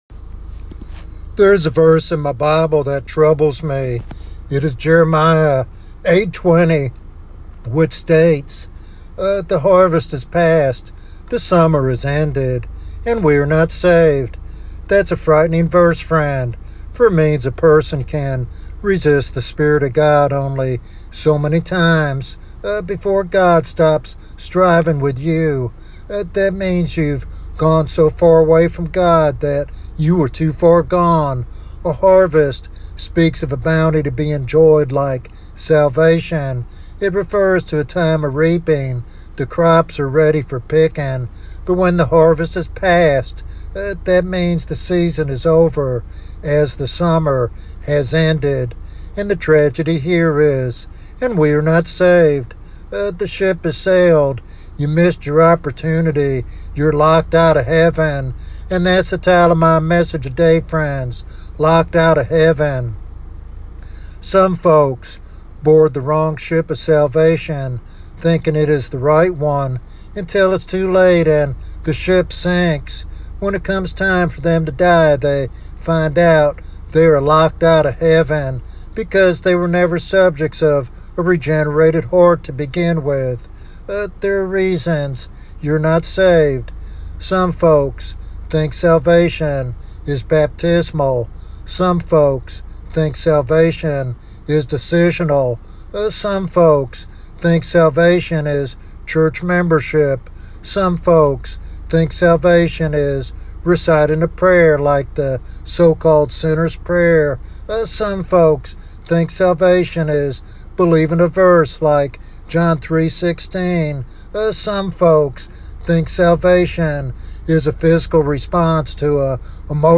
In this compelling evangelistic sermon